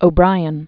(ō-brīən), Edna Born 1932?